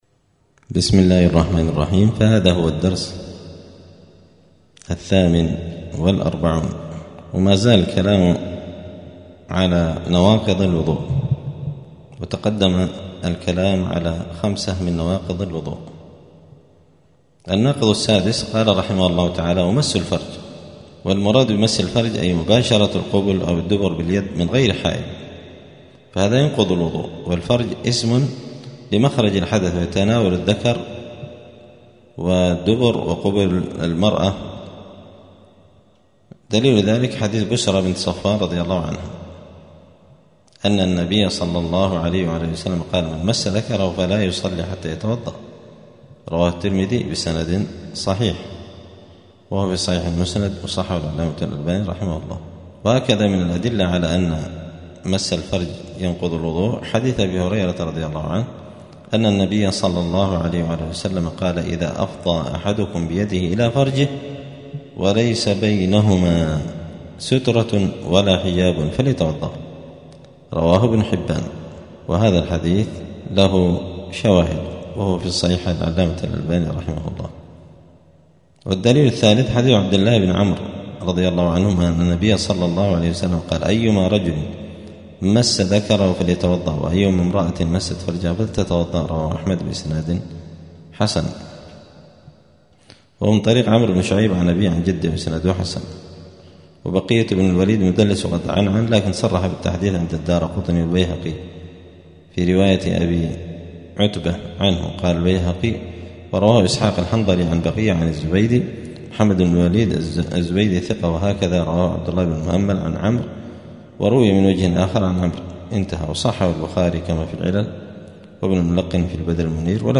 الثلاثاء 24 جمادى الأولى 1446 هــــ | الدروس، دروس الفقة و اصوله، كتاب روضة الناظرين شرح منهج السالكين | شارك بتعليقك | 16 المشاهدات
دار الحديث السلفية بمسجد الفرقان قشن المهرة اليمن